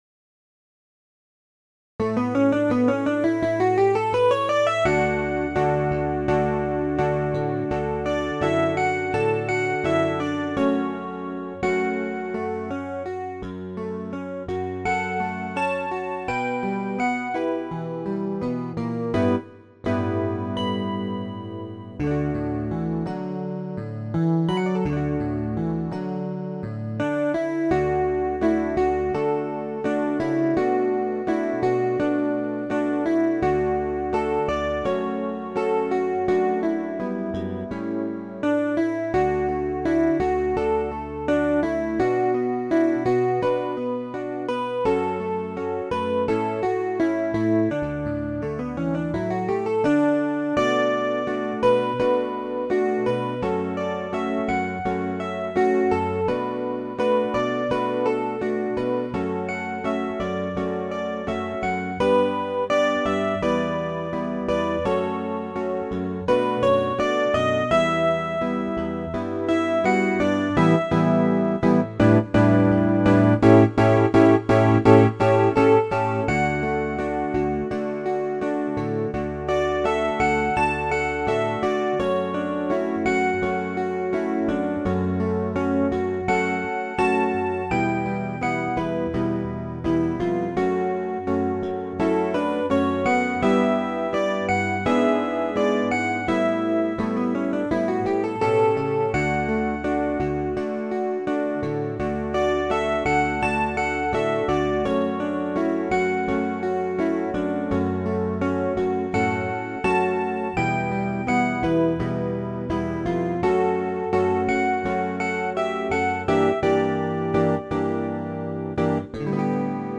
それをギター合奏にアレンジしました。
ギター3パートとバスギターの構成で、1stと2ndはハイポジションをかなり多用しているので技術的には難しいです。